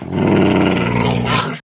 minecraft / sounds / mob / wolf / growl3.ogg
growl3.ogg